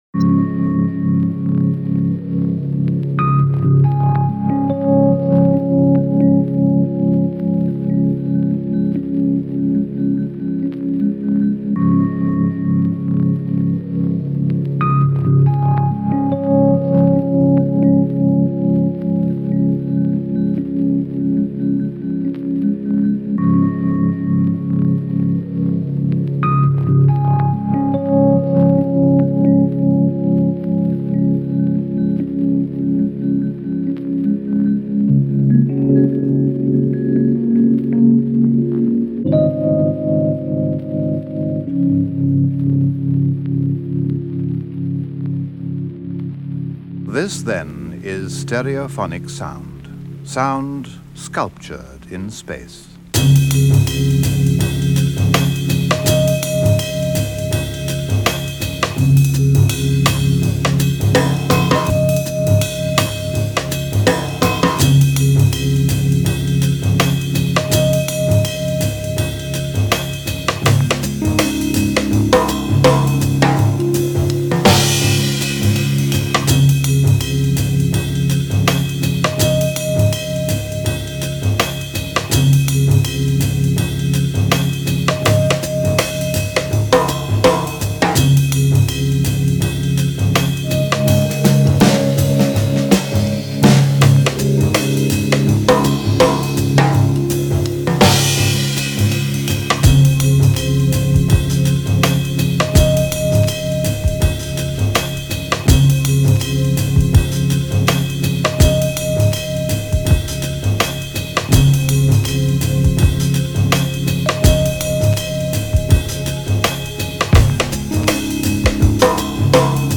Polish nu jazz duo